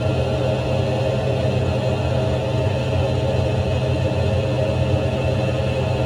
dockingbay.wav